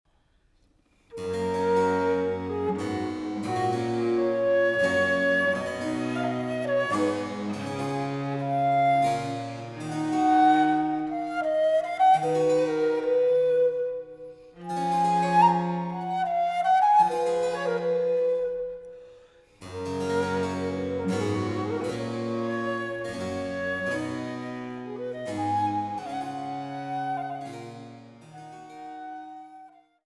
Menuet